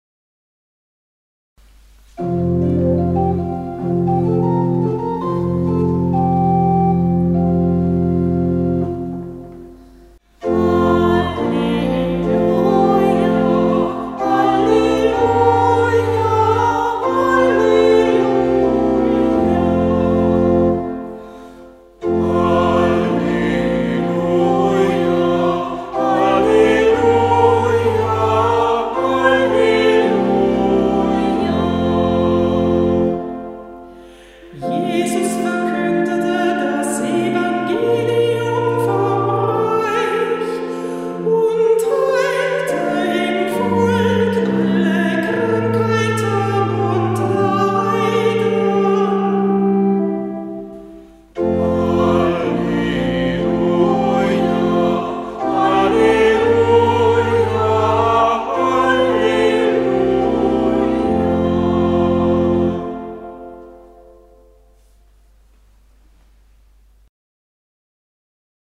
Ruf vor dem Evangelium - September 2024
Kantorin der Verse